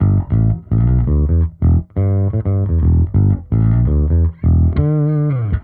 Index of /musicradar/dusty-funk-samples/Bass/85bpm
DF_JaBass_85-E.wav